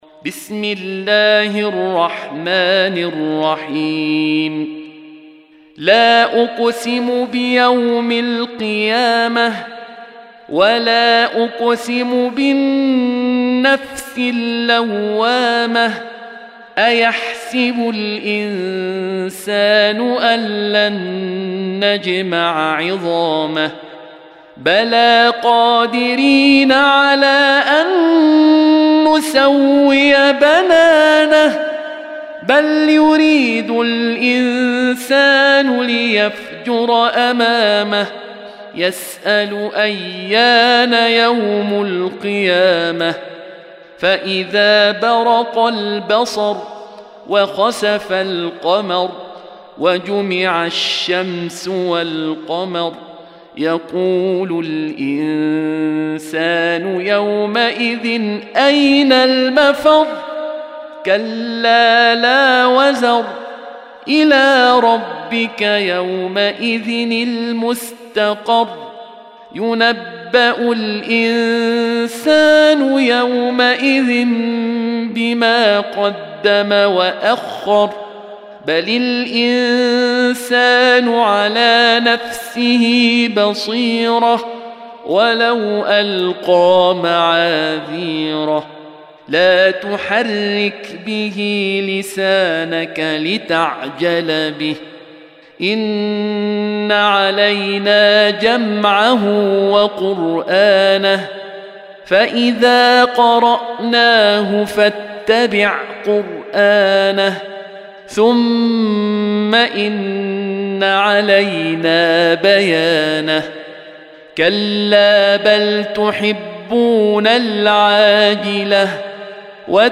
Audio Quran Tarteel Recitation
Surah Sequence تتابع السورة Download Surah حمّل السورة Reciting Murattalah Audio for 75. Surah Al-Qiy�mah سورة القيامة N.B *Surah Includes Al-Basmalah Reciters Sequents تتابع التلاوات Reciters Repeats تكرار التلاوات